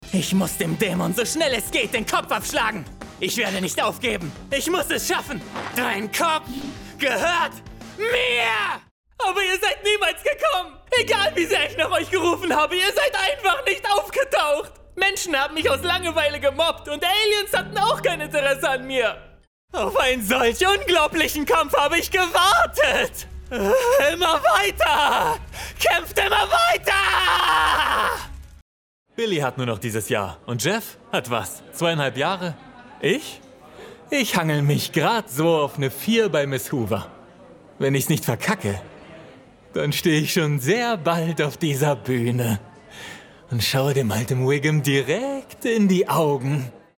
Acting Reel.mp3